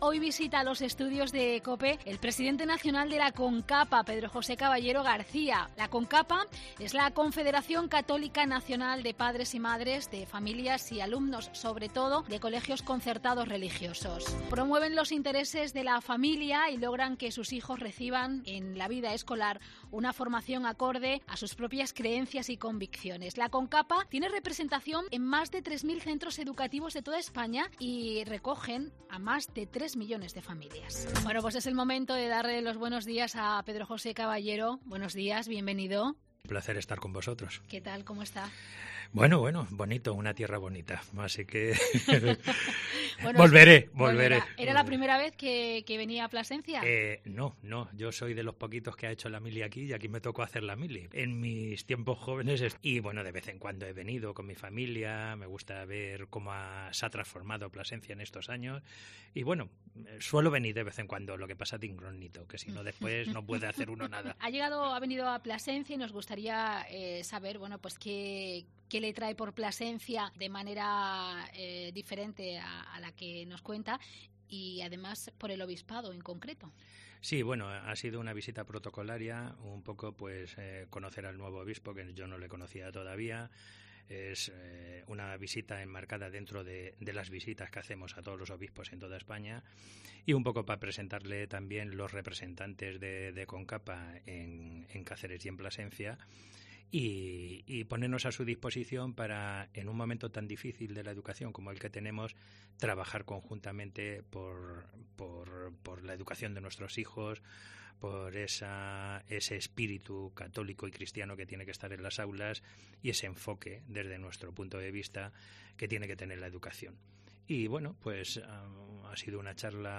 En esta visita ha tenido tiempo para sentarse frente al micrófono de COPE Plasencia y hablarnos de la problemática actual de escuela concertada en Extremadura y en España.